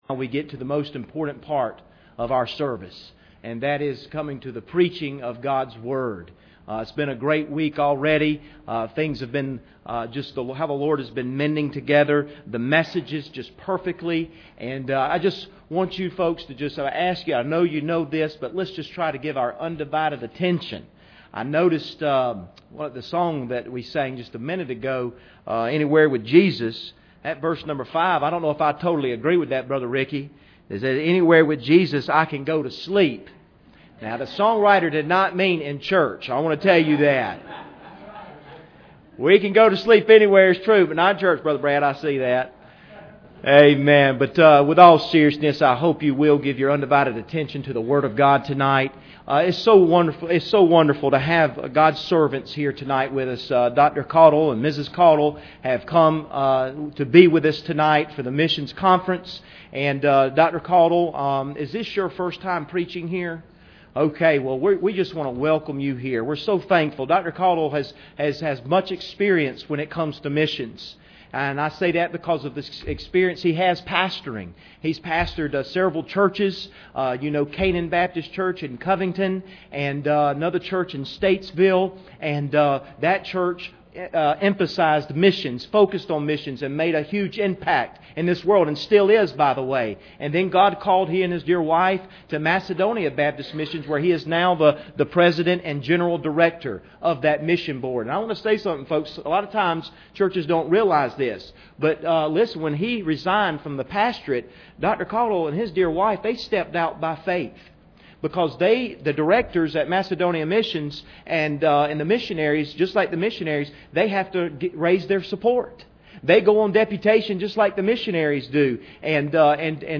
Philippians 4:10-17 Service Type: Special Service Bible Text